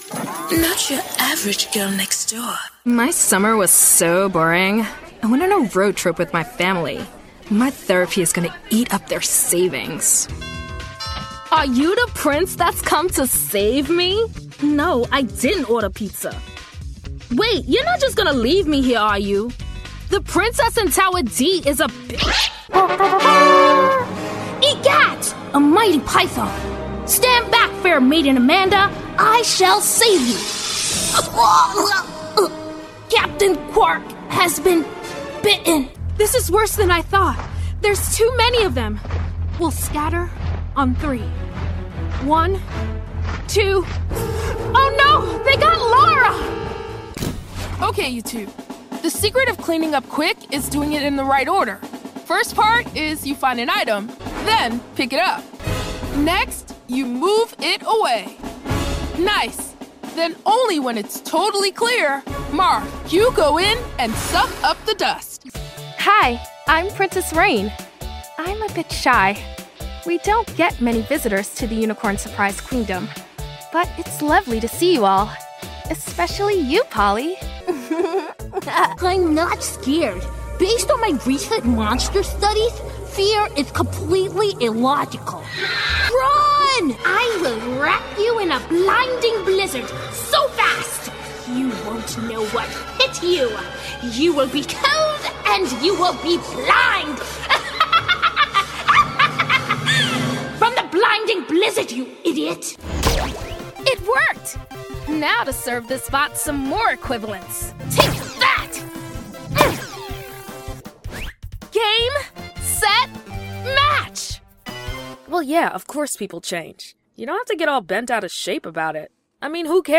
Child, Teenager, Young Adult, Adult
Has Own Studio
My voice is often described as versatile, relatable, warm but cool, and authentic. I can also play a variety of fun characters (from realistic boys to “mean girls” to sweet moms and engaging teachers).
black us
new york | character
southern us | natural
standard us | natural
ANIMATION 🎬